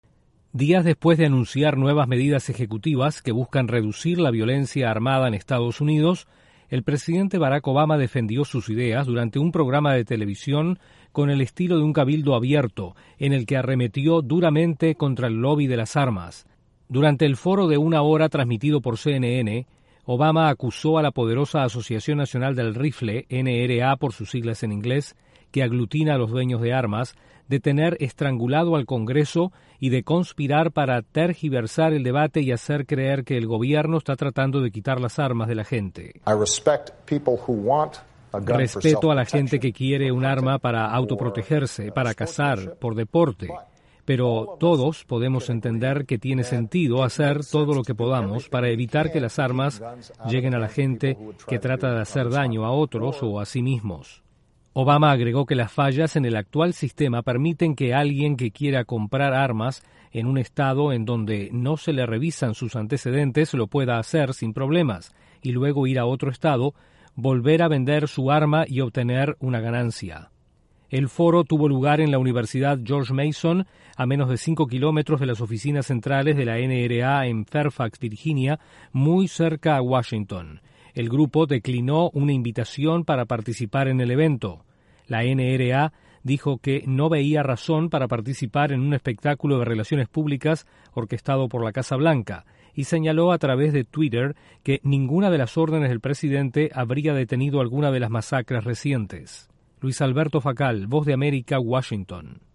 El presidente Barack Obama defendió sus ideas sobre el control de armas durante un cabildo abierto en Virginia. Desde la Voz de América en Washington informa